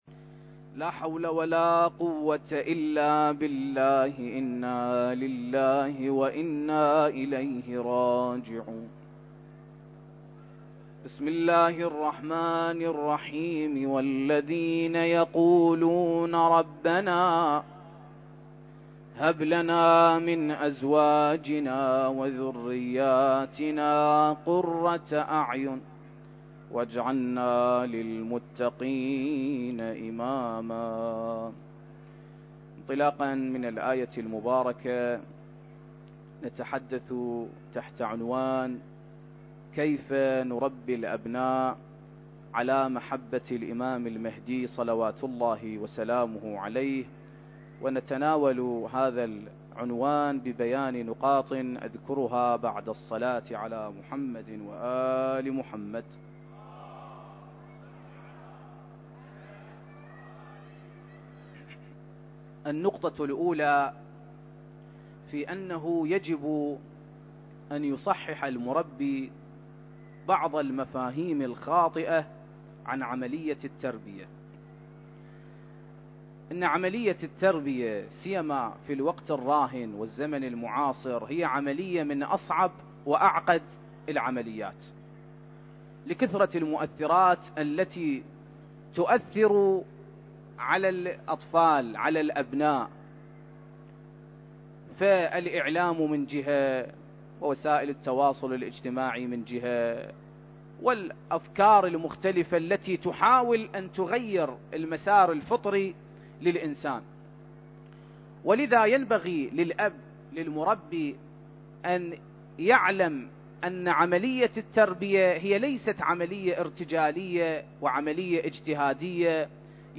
المكان: العتبة الحسينية المقدسة التاريخ: 2023